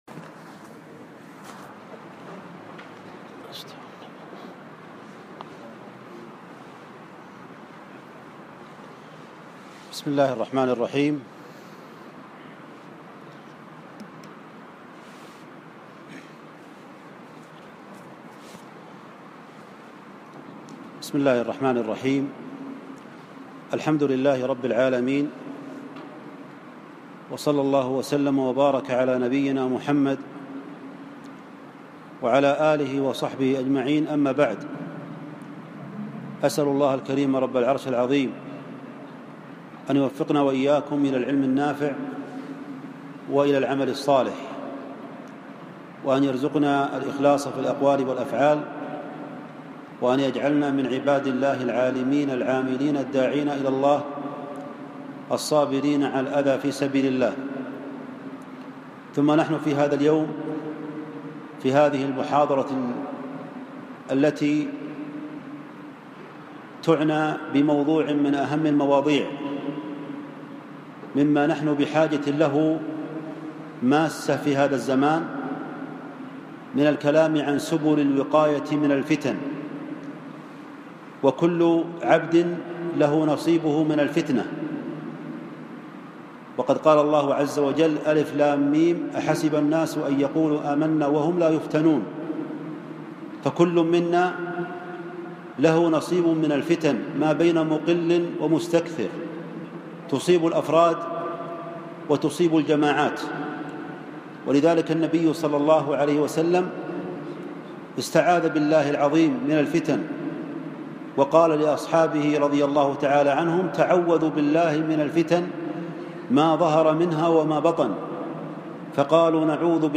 بعد مغرب الأحد 4 6 1437 جامع الملك فهد بحي العزيزية الطائف